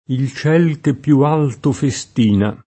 festino [ fe S t & no ]